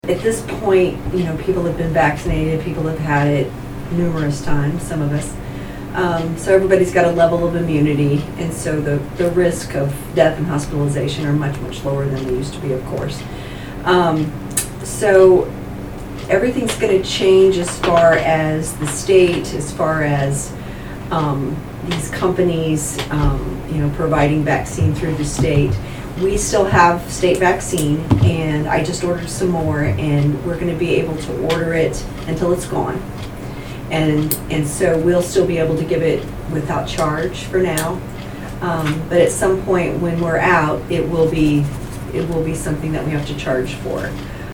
HEALTH DEPARTMENT SPEAKS ABOUT THE END OF THE PANDEMIC AT COUNTY COMMISSION MEETING
The Saline County Health Department discussed the end of the COVID-19 Pandemic during the Saline County Commission meeting on Wednesday, May 10.